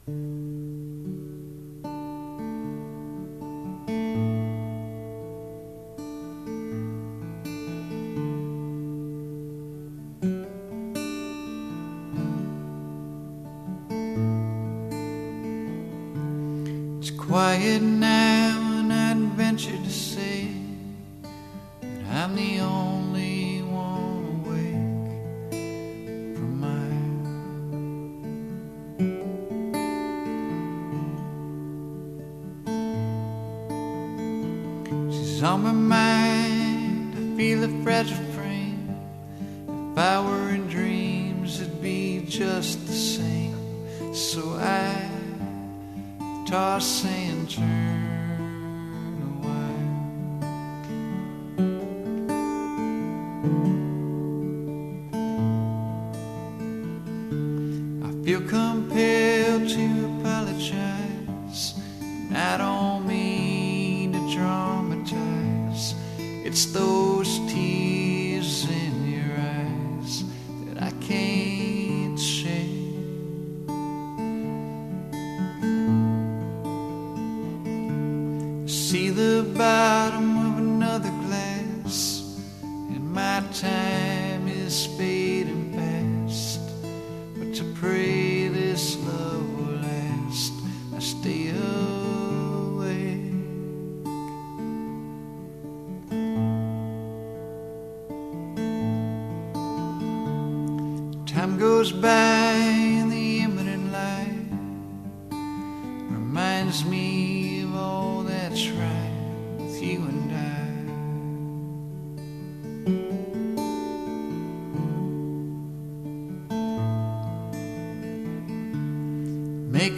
good stuff. this could really benefit from some vocal harmonies.
Man, this is sad :lol:
I really like how underscored the guitar is, your vocal is definitely good enough to carry the song.
Kind of a Ryan Adams acoustic with amazing vocals.
Vocals are strong as always..